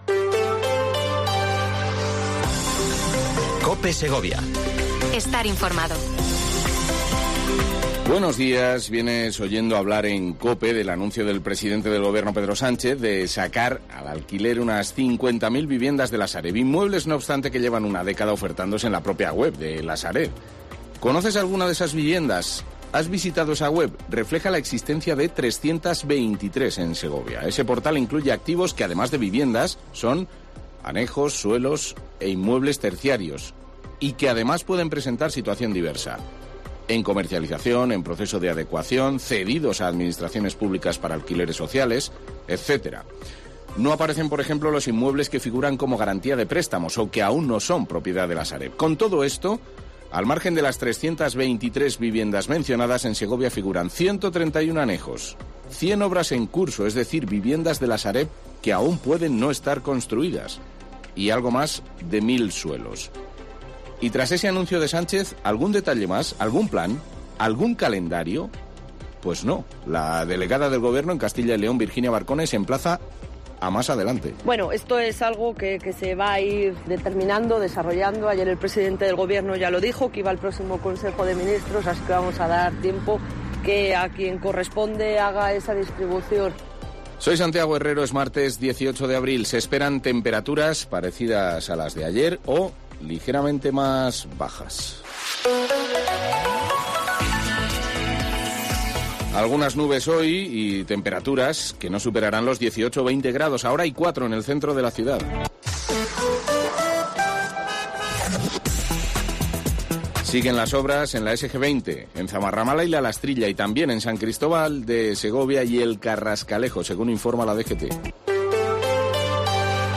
Informativo local Herrera en Cope Segovia, 8:24h. 18 de abril